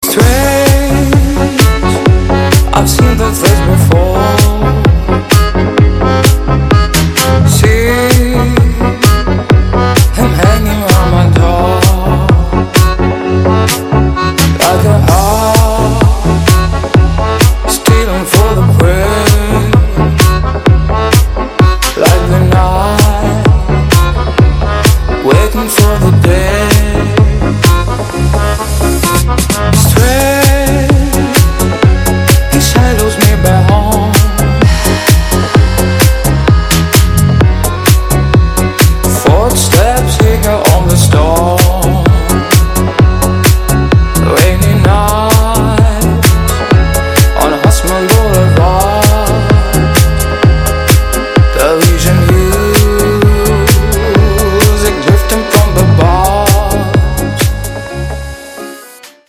Громкие рингтоны / Клубные рингтоны